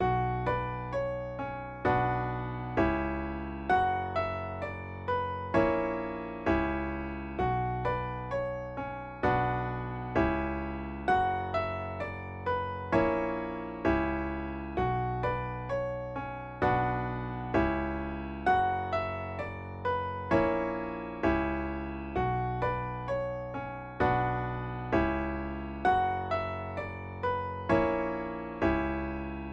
描述：我黑暗合唱团的音频炼金术。
Tag: 80 bpm Hip Hop Loops Choir Loops 2.02 MB wav Key : Unknown